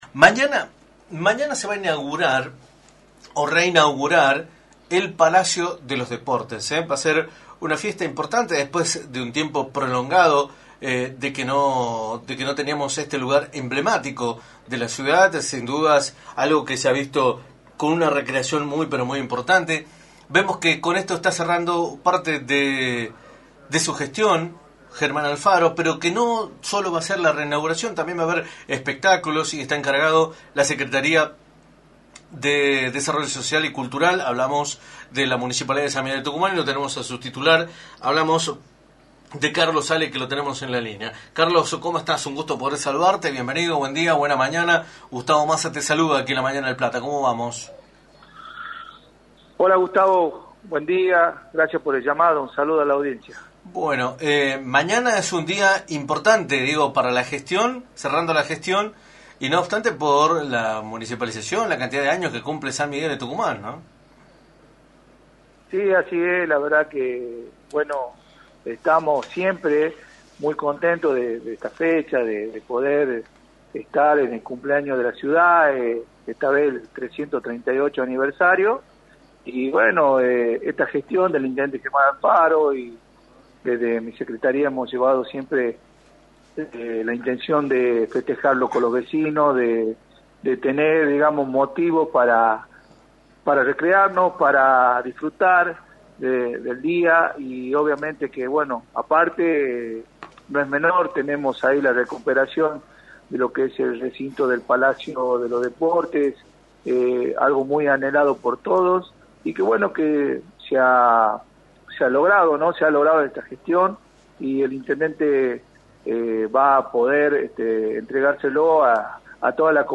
Carlos Ale, Secretario de Desarrollo Social y Cultural de la Municipalidad de San Miguel de Tucumán y Concejal electo, por la 93.9, informó en Radio del Plata Tucumán, por la 93.9, cuales son las actividades previstas por el Día de la Ciudad, festejos que tendrán como protagonista a la re inauguración del Palacio de los Deportes, a partir de las 21.